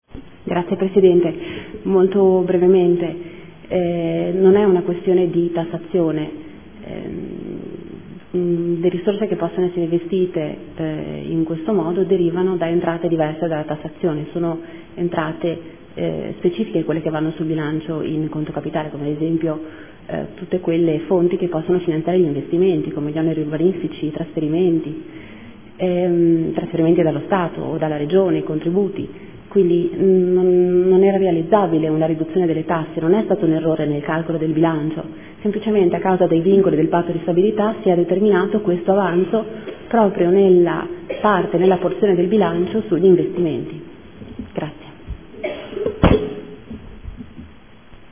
Seduta del 27/11/2014 Repliche a dibattito.